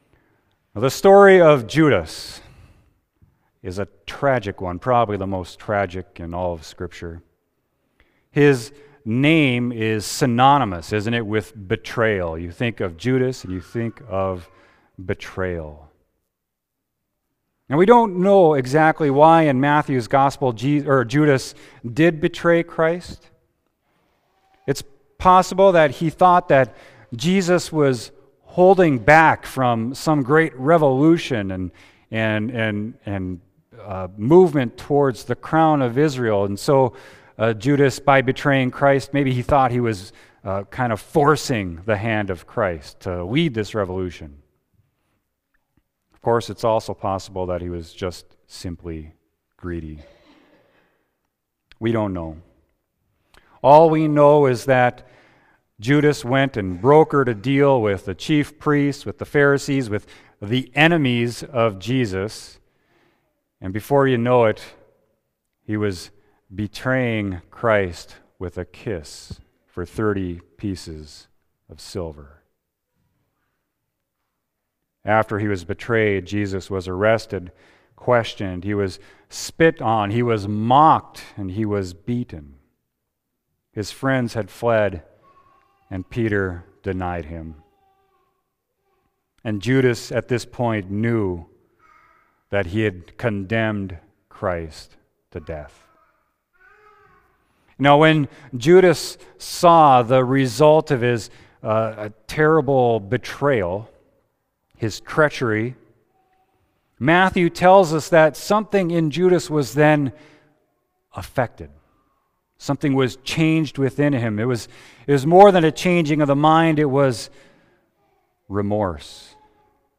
Sermon: Matthew 21.28-32